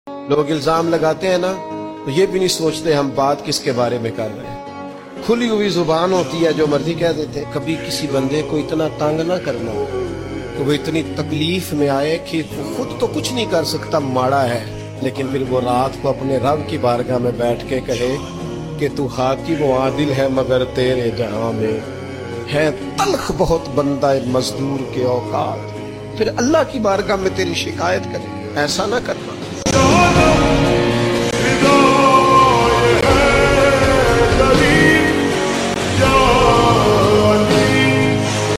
heart touching speech